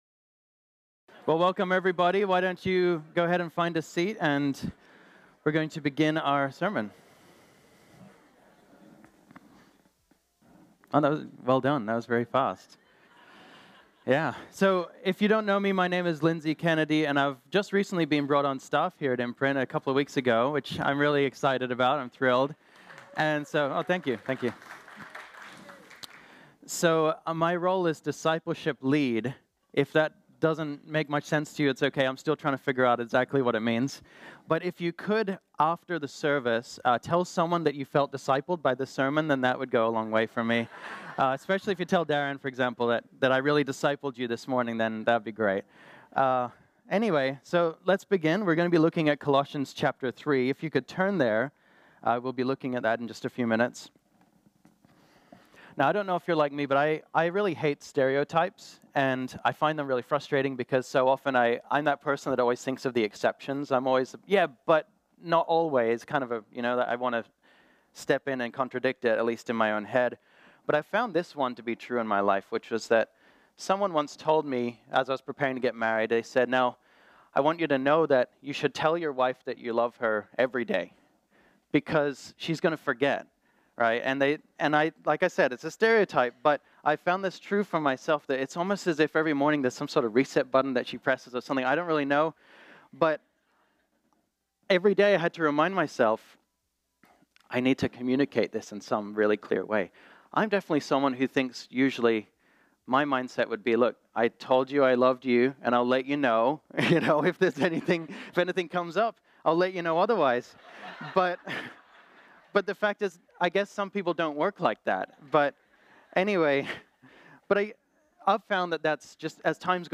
This sermon was originally preached on Sunday, November 4, 2018.